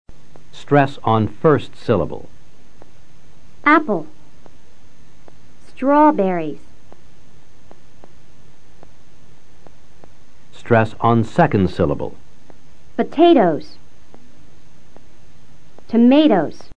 Escucha, en los próximos dos audios, la acentuación de estos sustantivos.